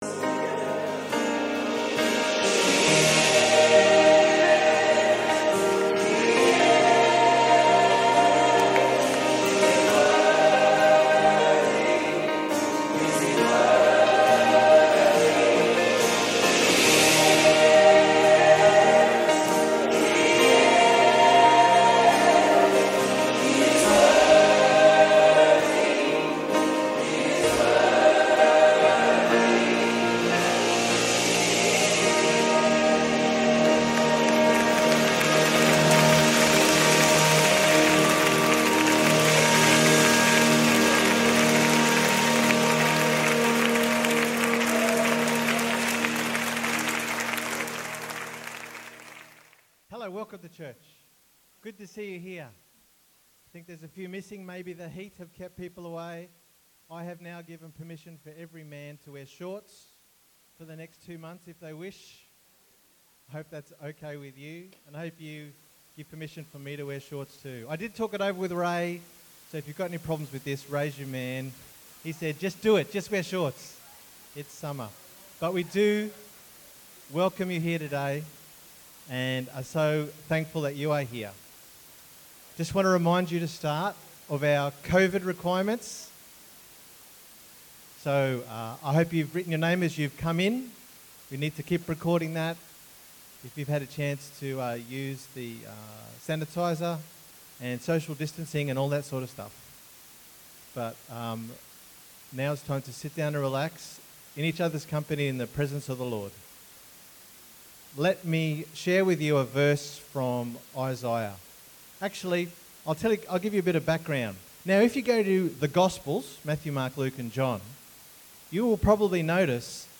Sunday_Meeting_6th_December_2020_Audio.mp3